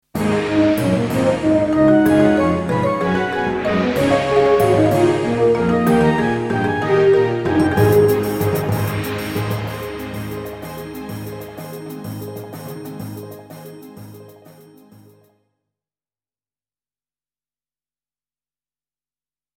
Sterke intromuziek met uitloop